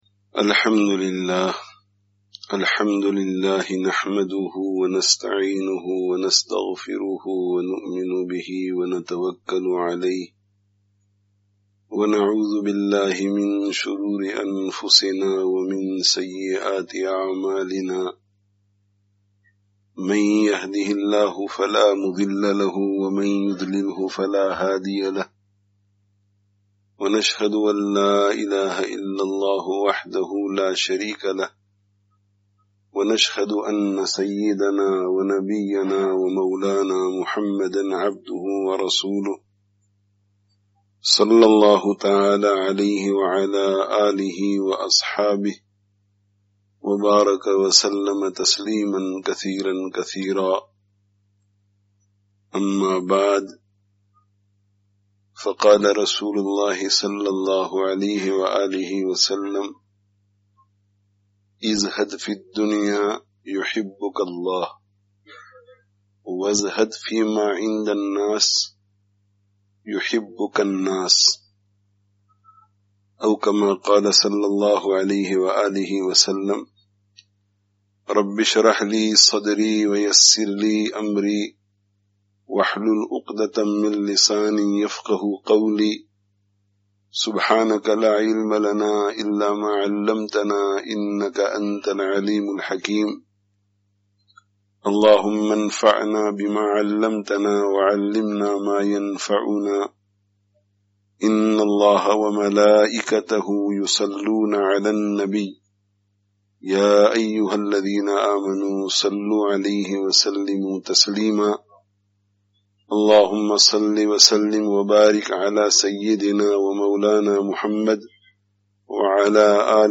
Download friday tazkiyah gathering Urdu 2020 Related articles Allāh ta'ālā kī Farmā(n)bardārī me(n) Kāmyābī hī Kāmyābī hai (14/08/20) Be Shumār Ni'mato(n) ke Bāwajūd Mahrūmī kā Ihsās?